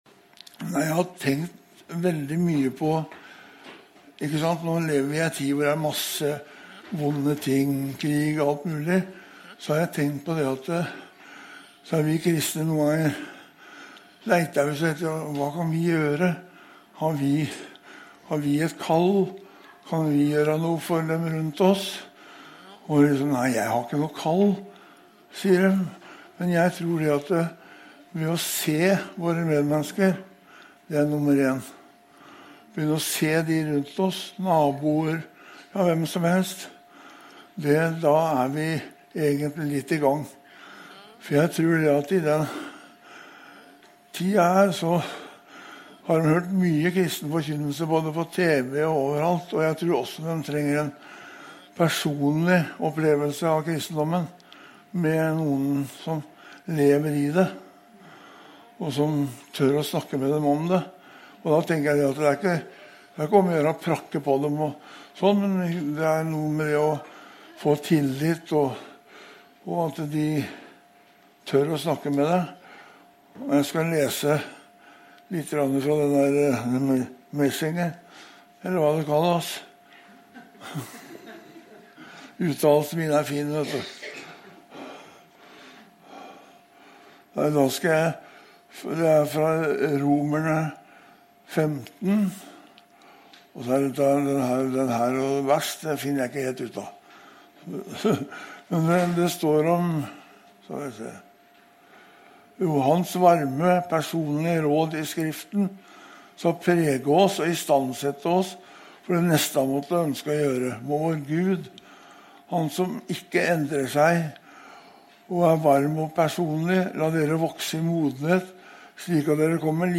Tale
Flygel etterspill